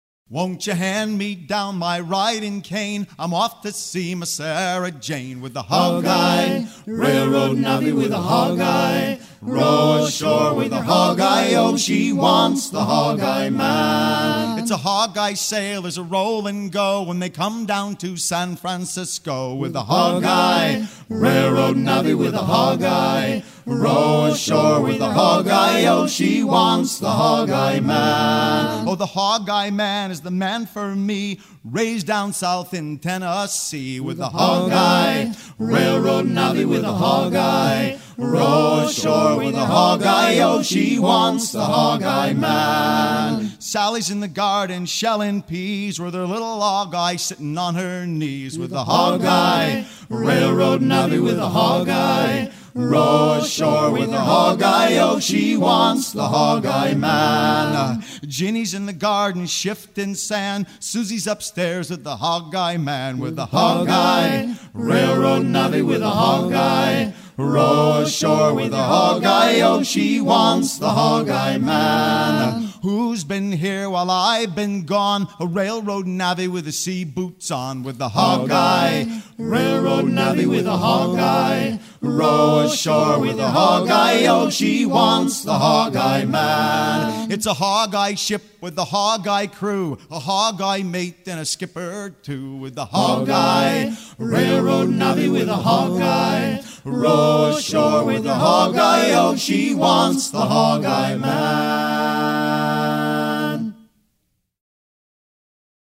gestuel : à pomper ; gestuel : à virer au guindeau
circonstance : maritimes
Pièce musicale éditée